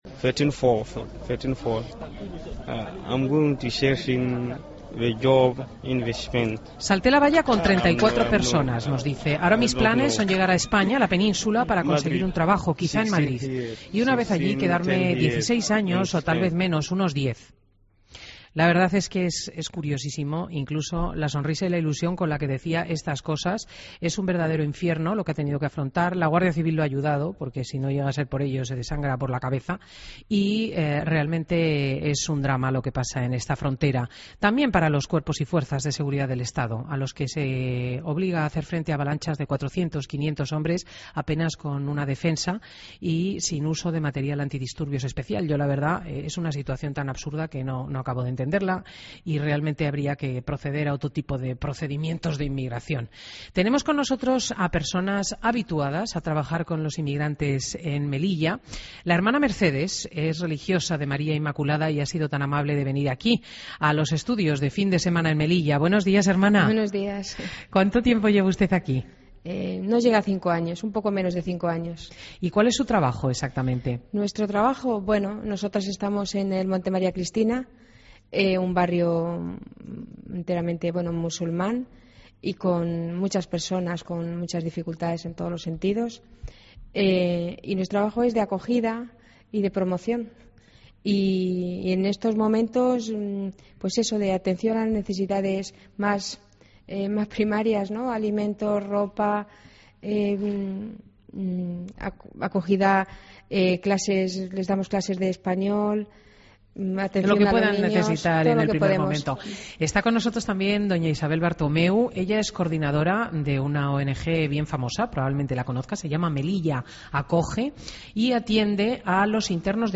Entrevistas en Fin de Semana